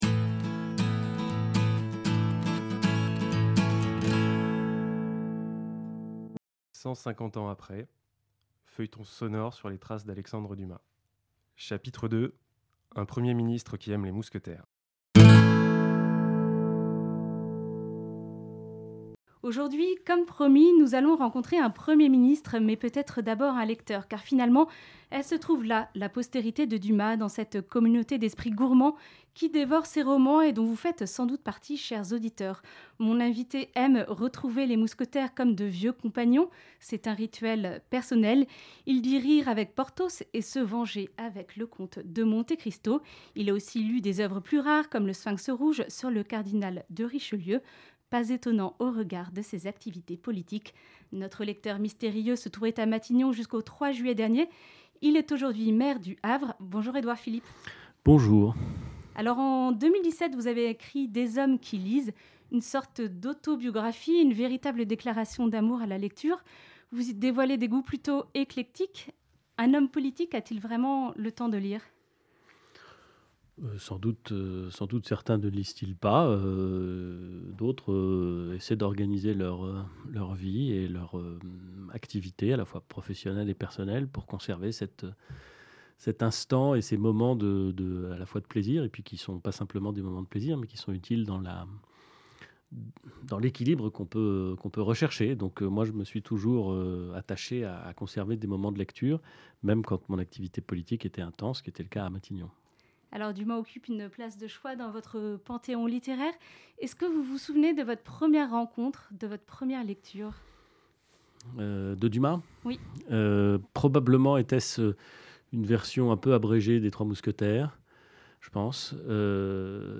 150 ans après : Un feuilleton sonore sur les traces d’Alexandre Dumas
Edouard Philippe est avec nous pour un entretien inédit sur Alexandre Dumas, son auteur préféré.